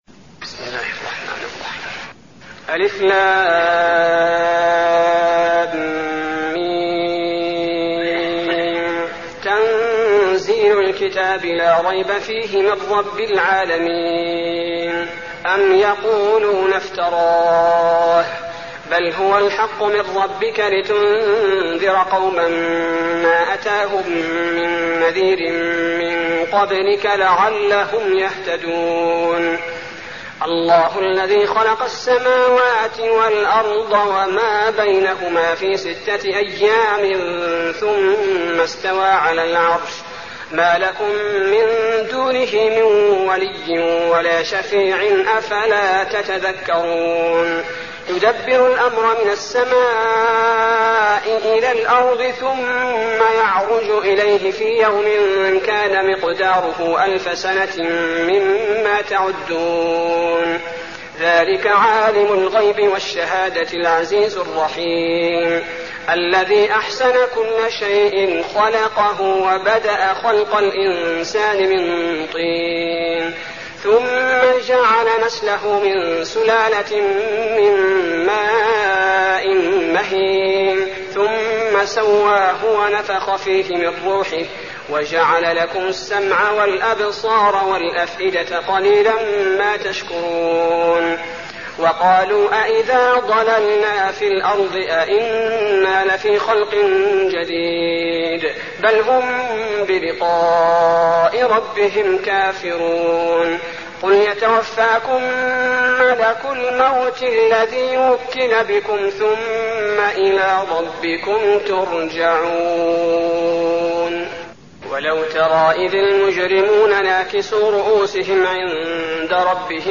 المكان: المسجد النبوي السجدة